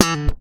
ALEM FUNK E5.wav